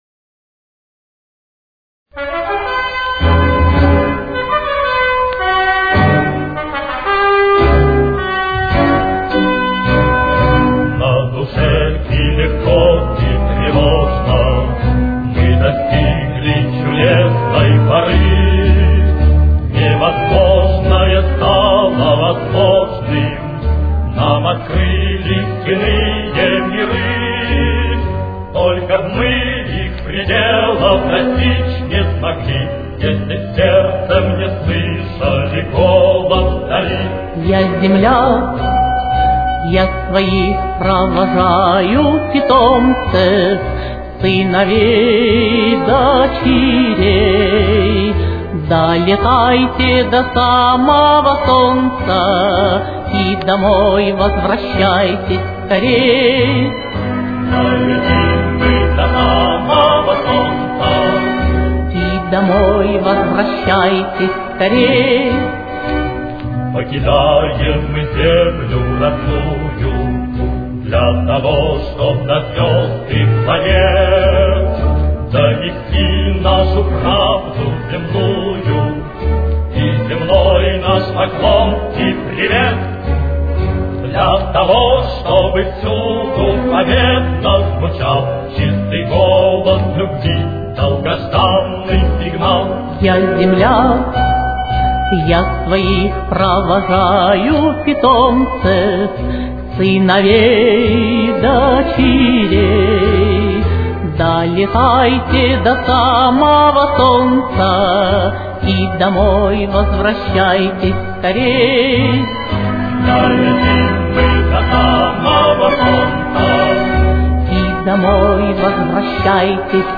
с очень низким качеством (16 – 32 кБит/с)
Си минор. Темп: 111.